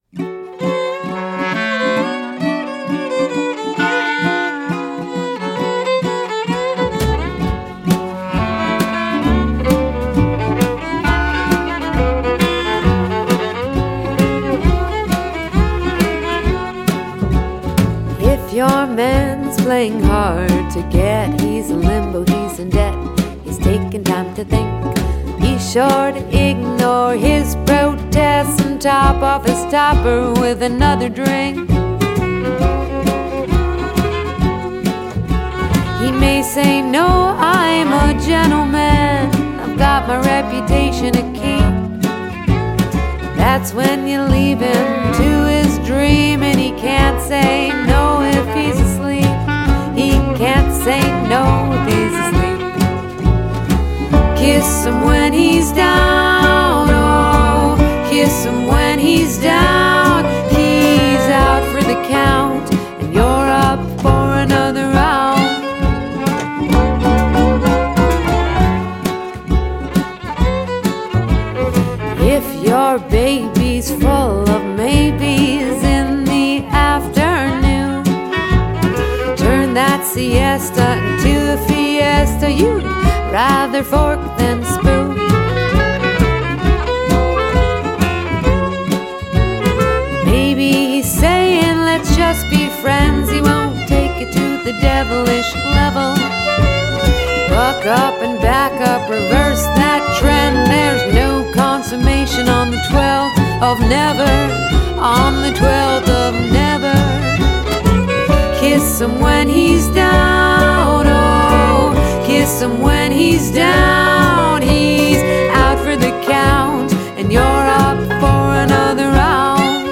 indie folk tunes